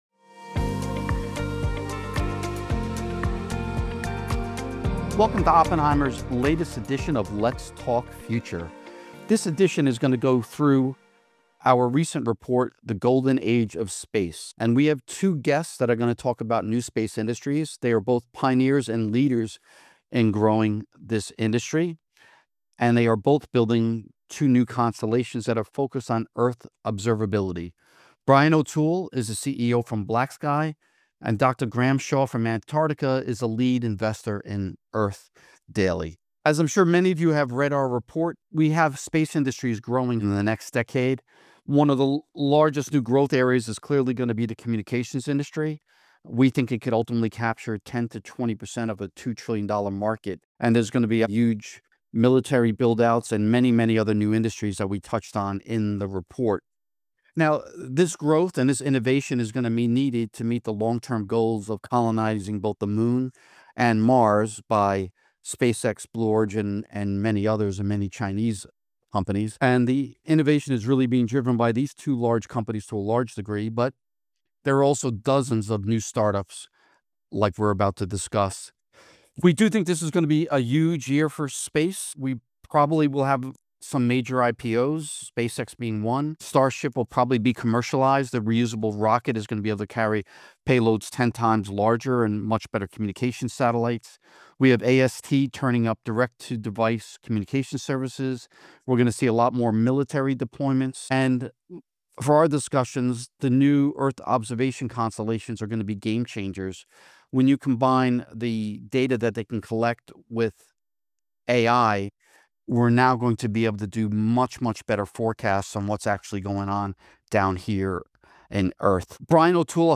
A Podcast Conversation